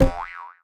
reward_drop_05.ogg